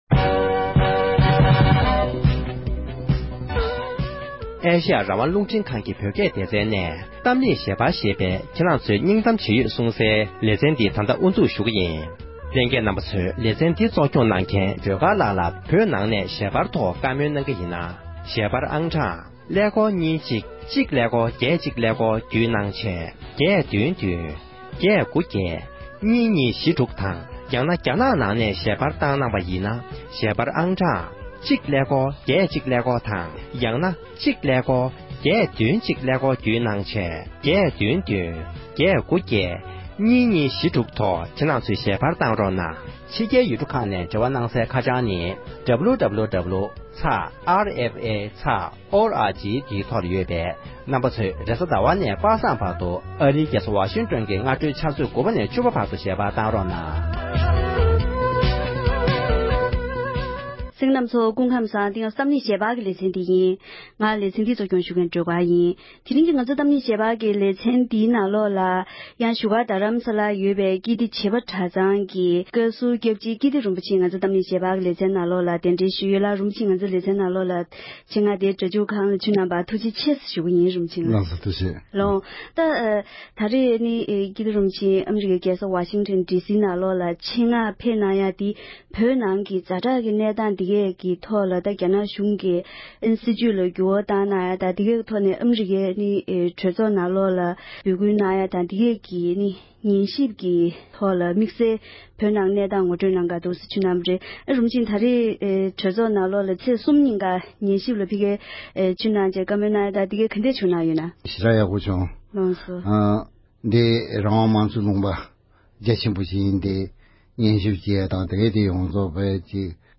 ཀིརྟི་རིན་པོ་ཆེ་མཆོག་གིས་བོད་ཨ་མདོ་རྔ་བ་ཁུལ་གྱི་ཛ་དྲག་གནས་སྟངས་སྐོར་དང་དེ་བཞིན་བོད་ནང་གི་ཀིརྟི་དགོན་པའི་གནས་སྟངས་ངོ་སྤྲོད་གནང་བ།